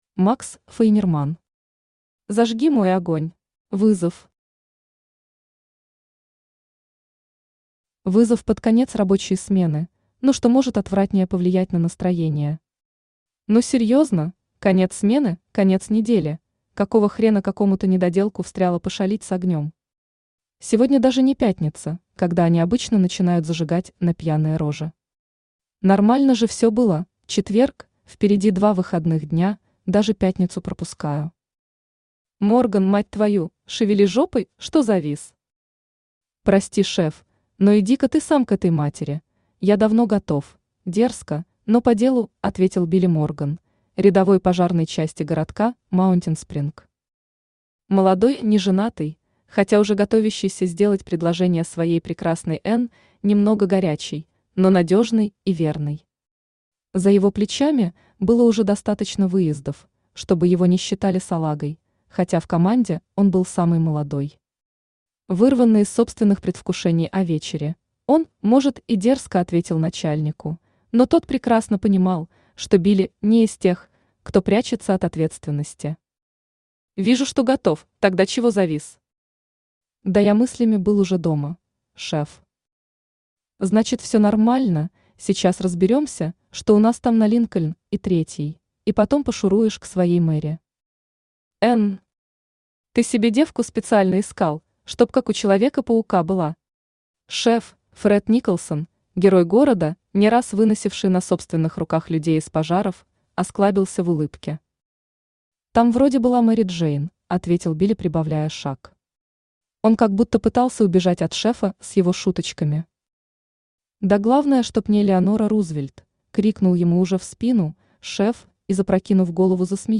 Аудиокнига Зажги мой огонь | Библиотека аудиокниг
Aудиокнига Зажги мой огонь Автор Макс Файнерман Читает аудиокнигу Авточтец ЛитРес.